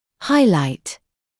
[‘haɪlaɪt][‘хайлайт]отмечать, выделять, подчеркивать; выдвигать на первый план